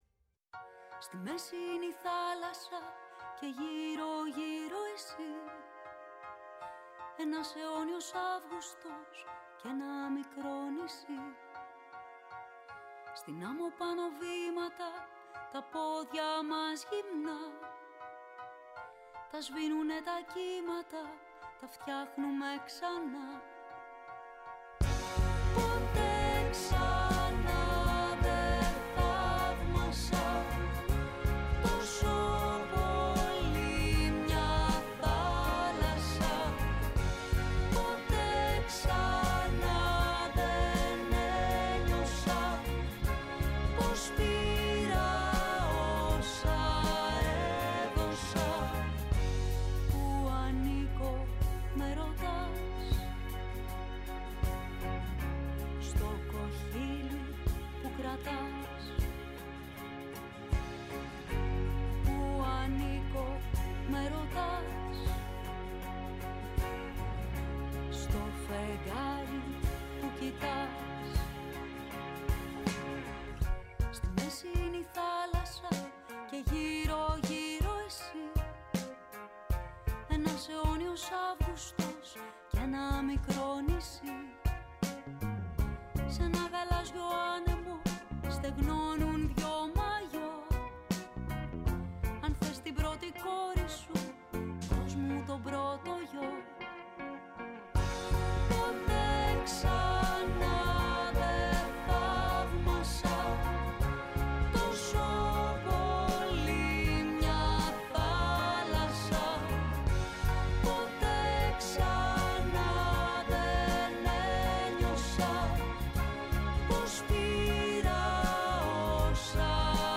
Η ΦΩΝΗ ΤΗΣ ΕΛΛΑΔΑΣ Κουβεντες Μακρινες ΟΜΟΓΕΝΕΙΑ ΣΥΝΕΝΤΕΥΞΕΙΣ Συνεντεύξεις